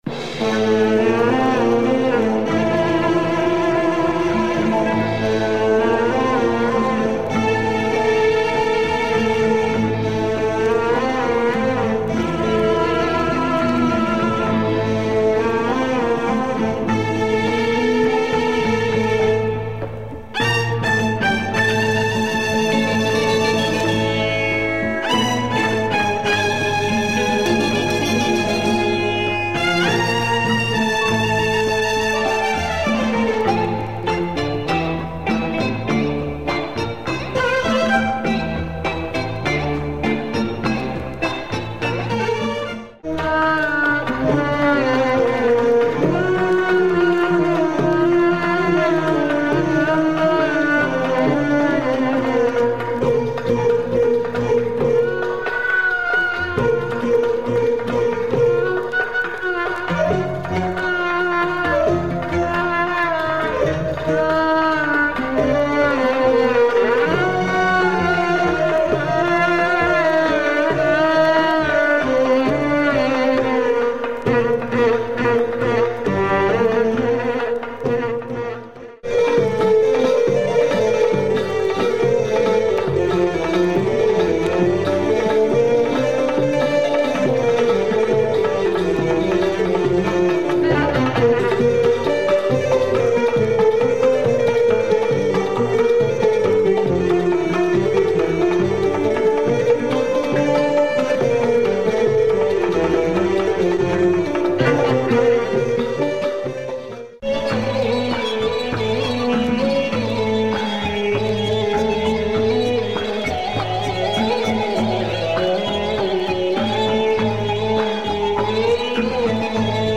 Acid organ Arabic groove !